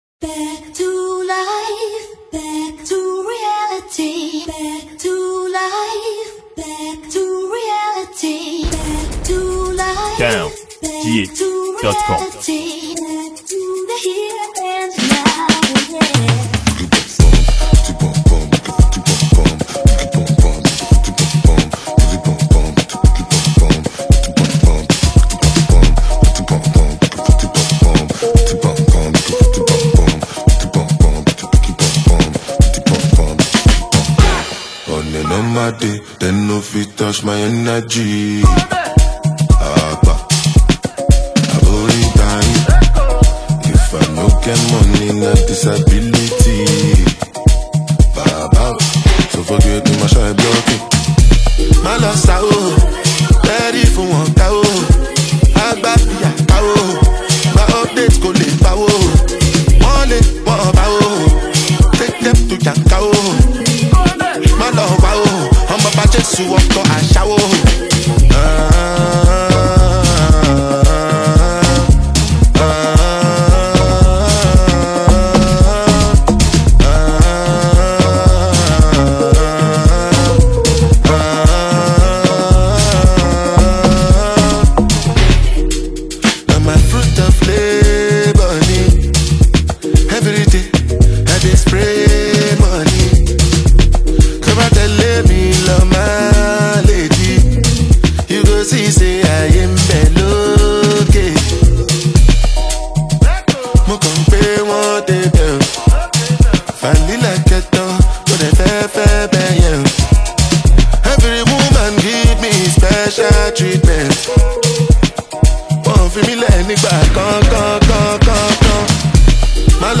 afrobeat